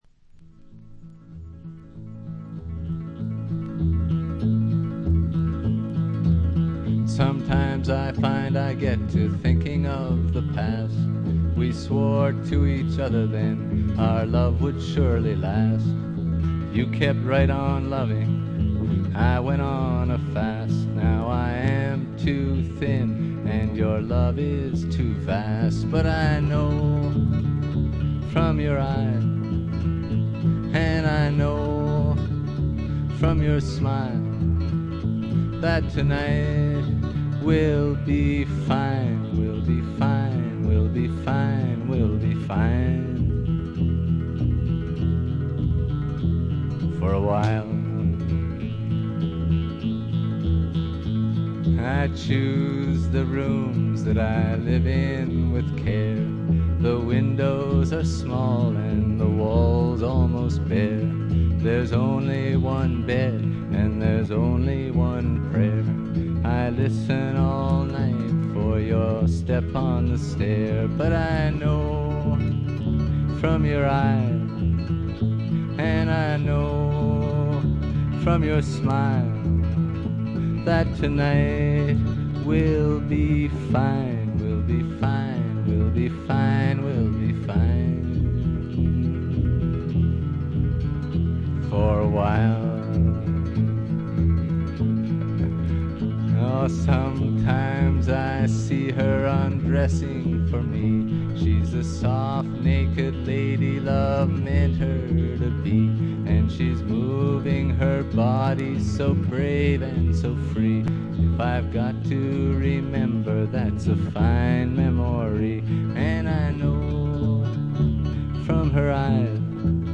Acoustic Guitar, Electric Guitar
Banjo, Bass, Violin, Acoustic Guitar
Keyboards